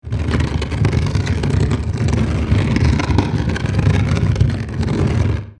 Wood Scrape; Heavy Wooden Object Drags Across Rough Surface With Rumble.